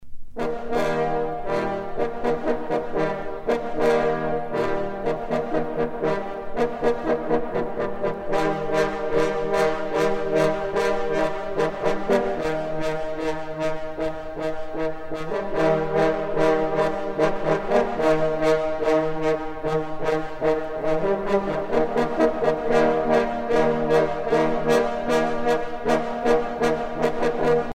circonstance : vénerie ;
Pièce musicale éditée